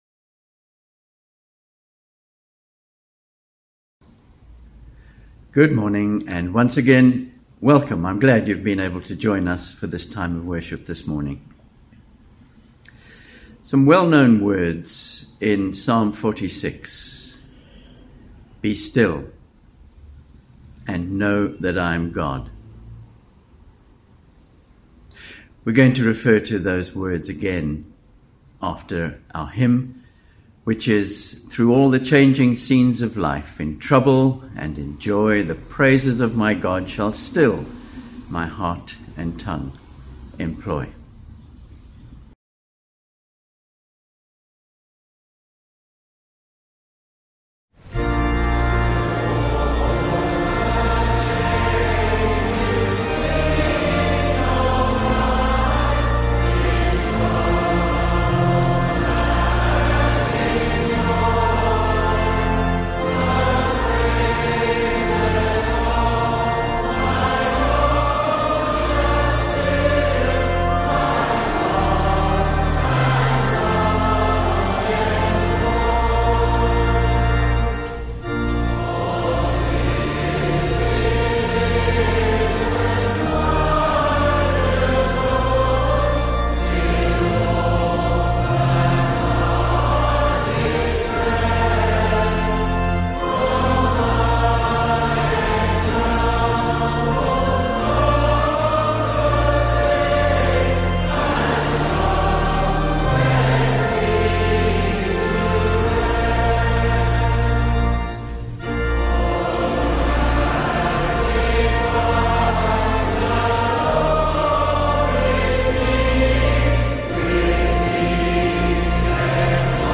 Sermon – 17th September – Time to grow up | Pinelands Methodist